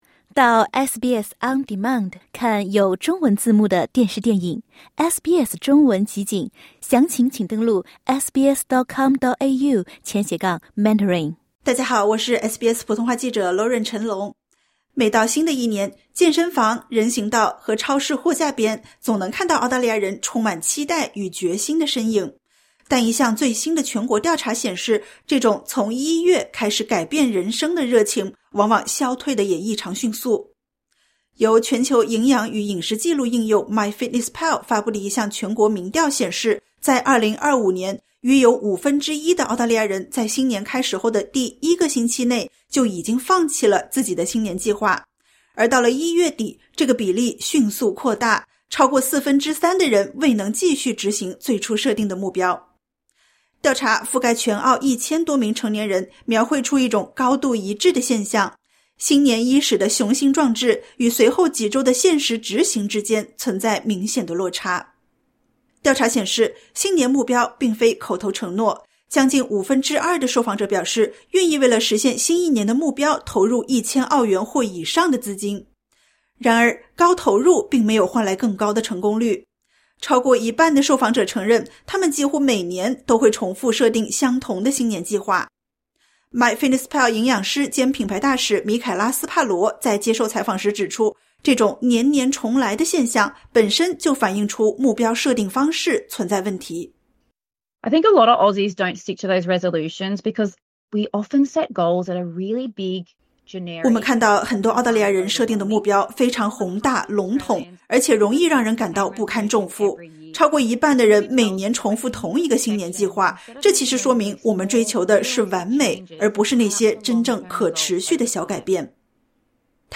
然而，一项最新全国性调查显示，这种“新年动力”往往消退得比想象中更快。点击 ▶ 收听完整报道。